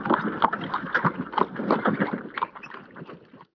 wagon2.wav